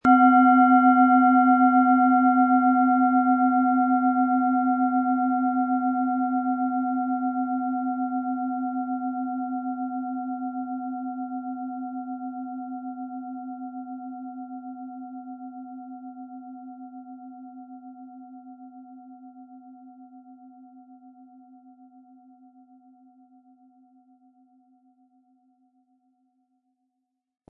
Sie sehen eine Planetenklangschale DNA, die in alter Tradition aus Bronze von Hand getrieben worden ist.
Ihre DNA-Klangschale wird mit dem beiliegenden Schlägel schön erklingen.
PlanetentöneDNA & Jupiter